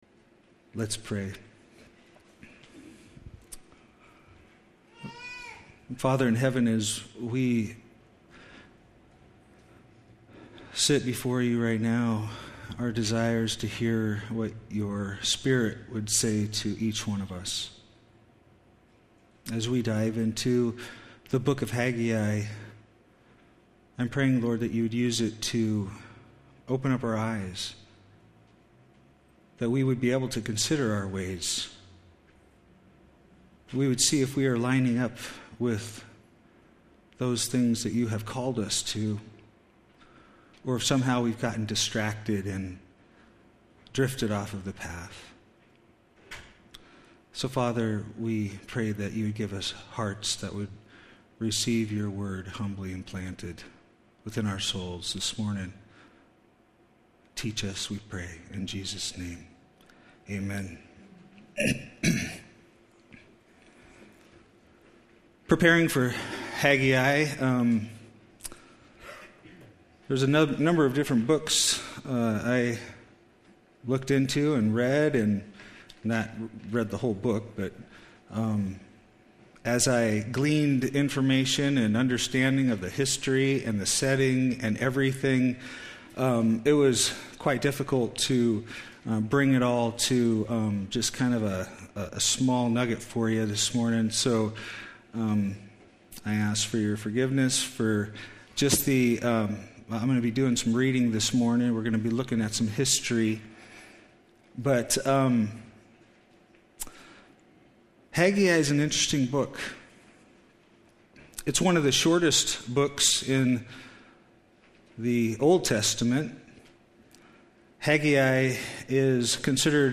2016 9:11 pm Awesome! it is good to be able to watch the sermon unfold!